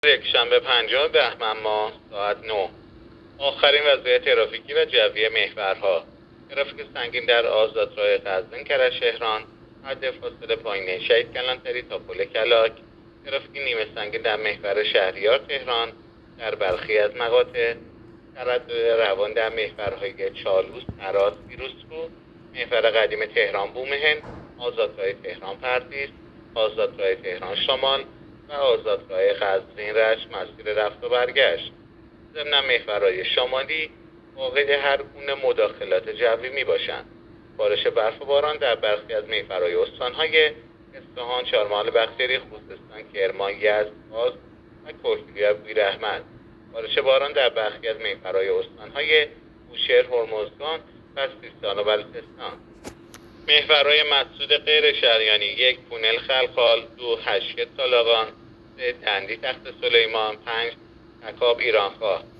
گزارش رادیو اینترنتی از آخرین وضعیت ترافیکی جاده‌ها ساعت ۹ پنجم بهمن؛